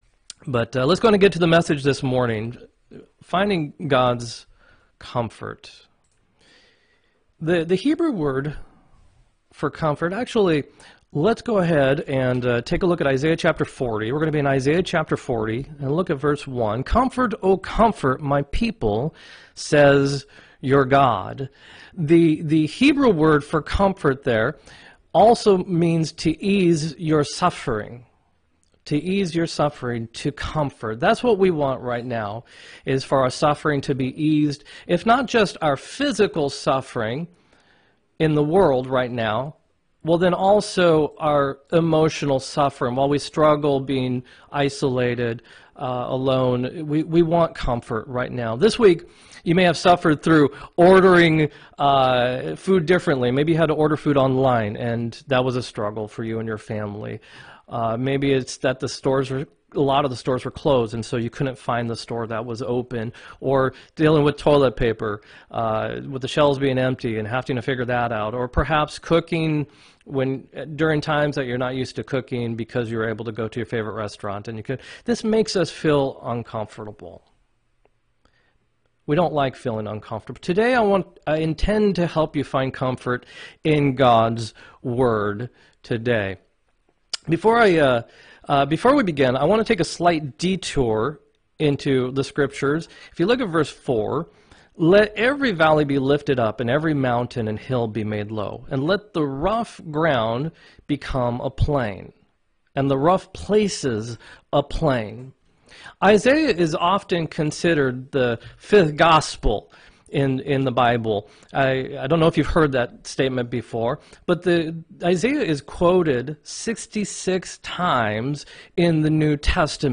3-28-20 sermon
3-28-20-sermon.m4a